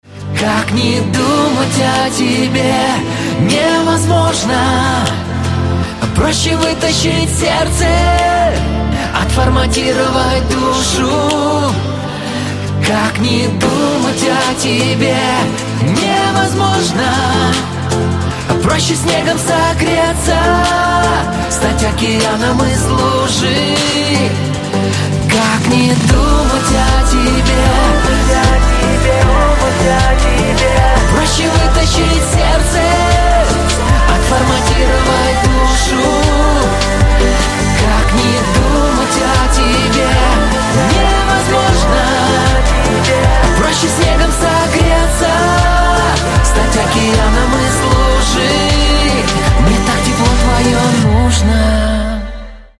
• Качество: 320, Stereo
поп
мужской вокал
лирика
романтичные